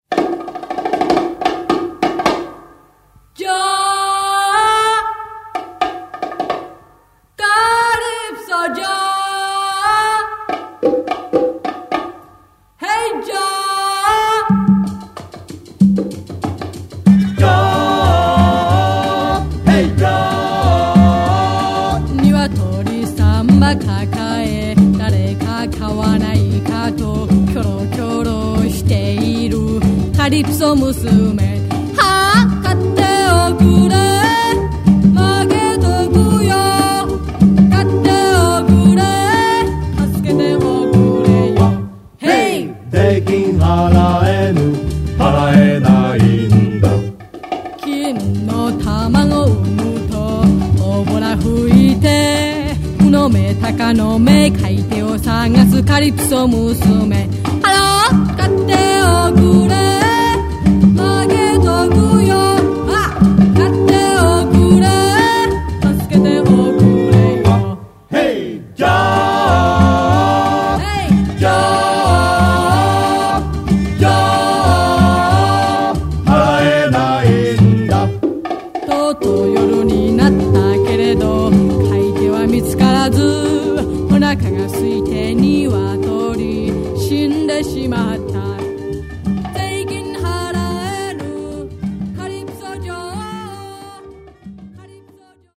原曲のエキゾチックなムードを遥かに超えた、ジャパニーズ・カリプソの最高傑
1957年の日本に確かに存在したカリプソ・ブーム。